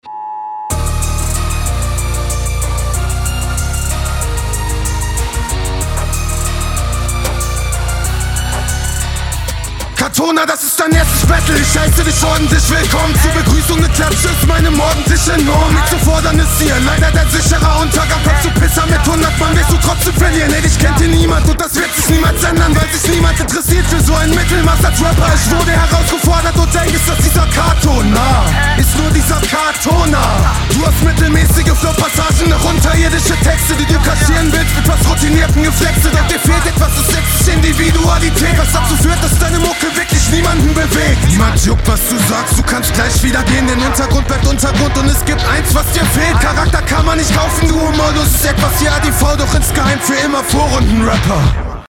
yo geiler Beat Sehr nice gerappt, allerdings finde ich das genau du hier einen "unterirdischen" …